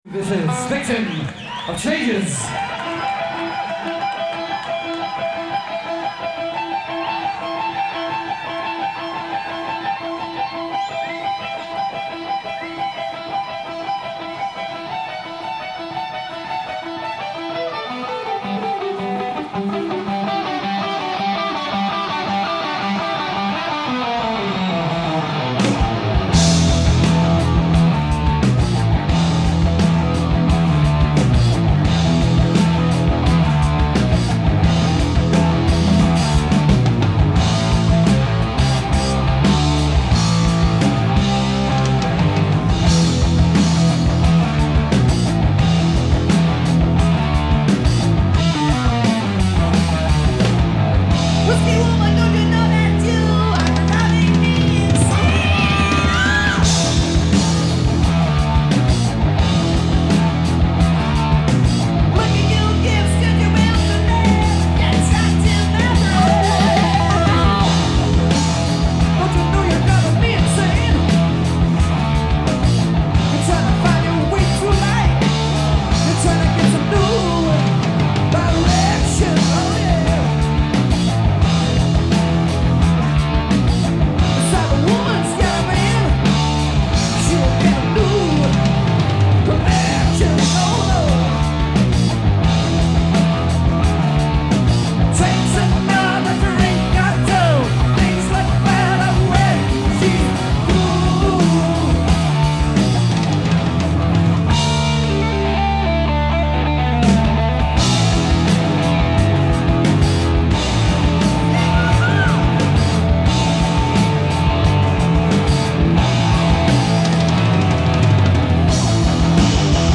Genre : Metal
Live at the Agora Theatre, Cleveland, 1978